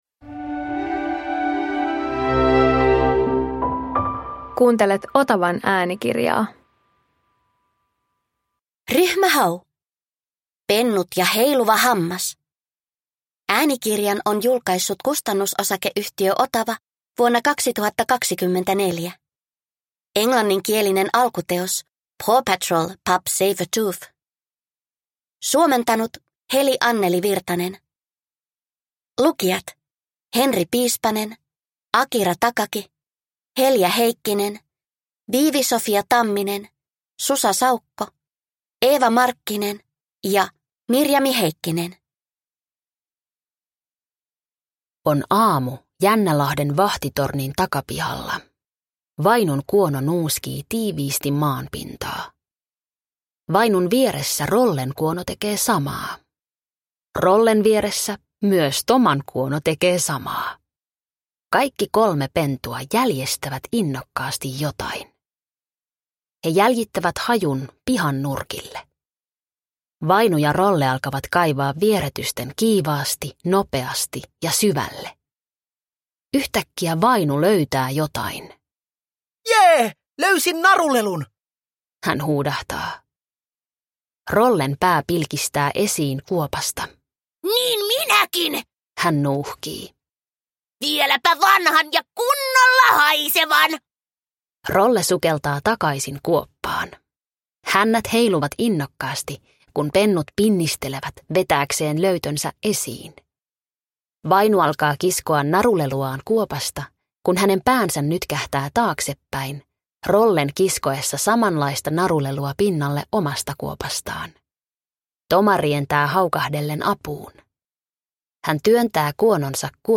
Ryhmä Hau - Pennut ja heiluva hammas – Ljudbok